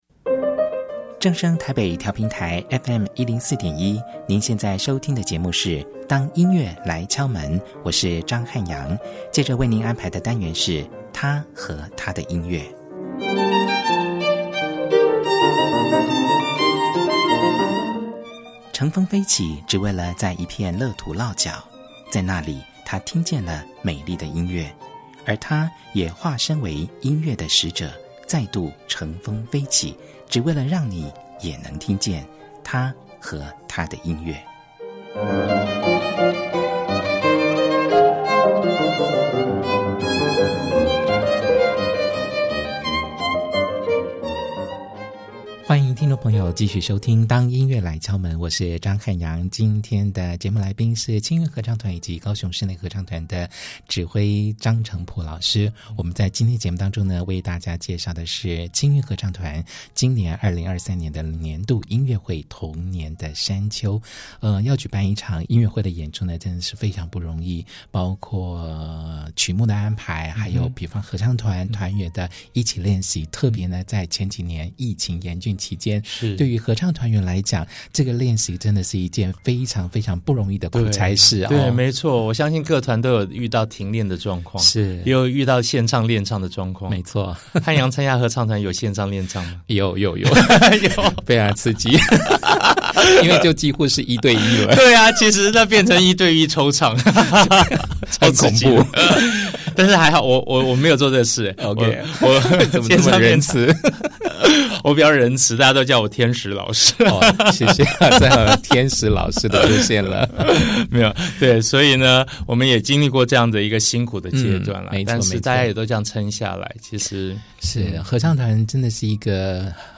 然後我們就在爆笑聲中展開一整集節目的對話。